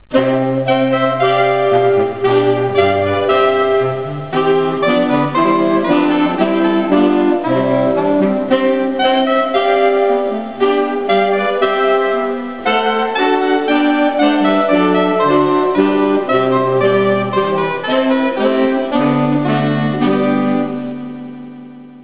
About Saxophone Quartets
The Bouree from Bach's Second Orchestral Suite. Formal music works very well on saxes - I wonder what J.S.B. would have written for them if they had been around in his time?